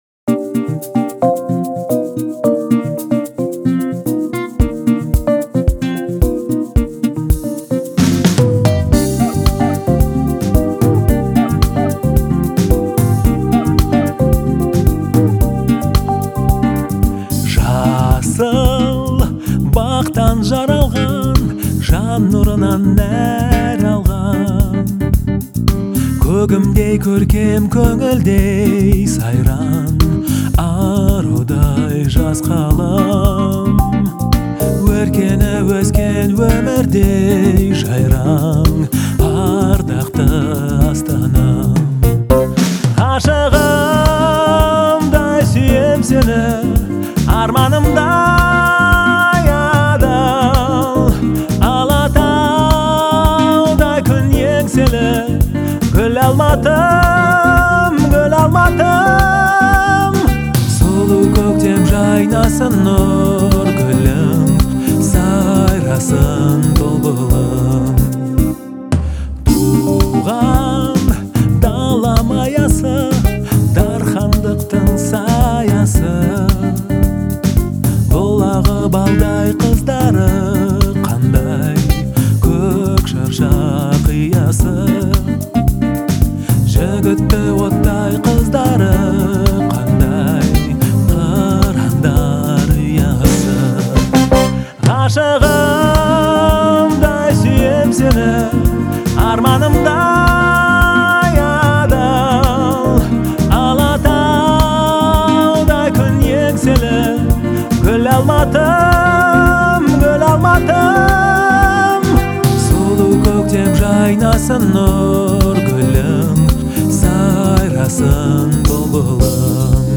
это динамичный и вдохновляющий трек в жанре поп-фолк.